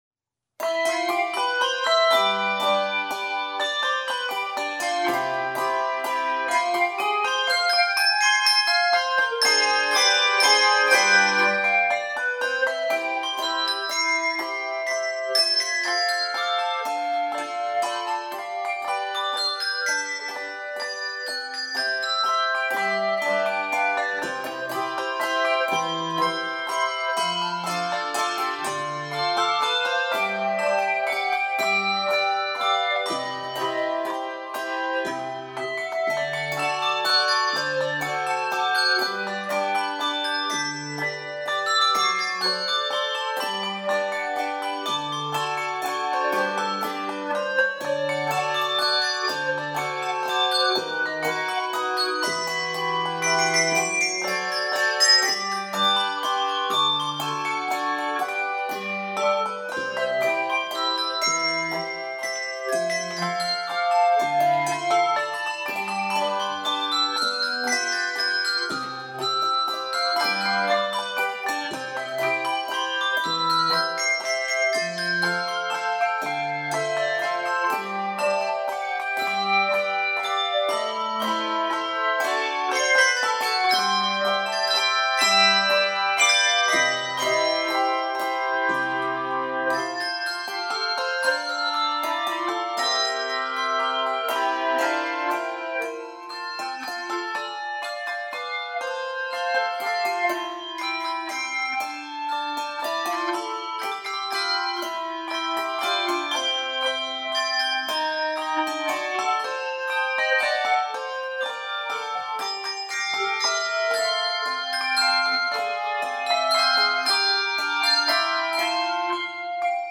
Voicing: Handbells 3-6 Octave